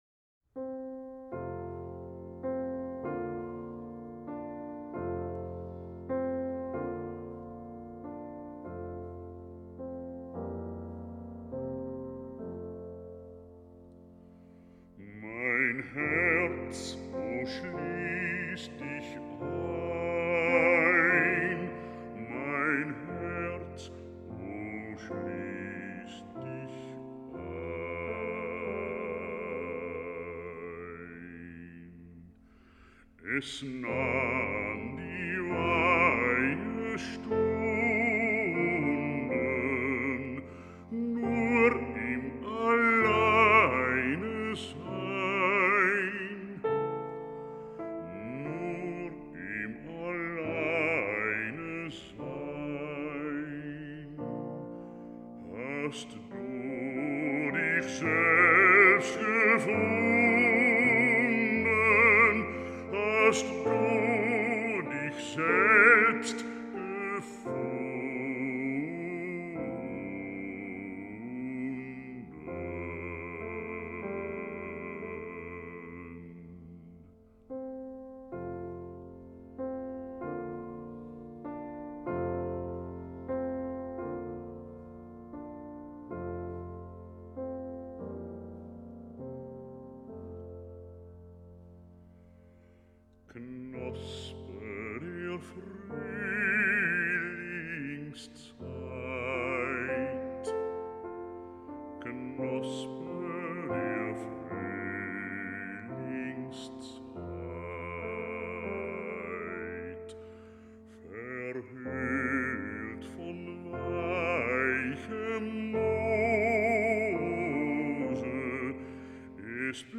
Robert Holl - Bariton,
Piano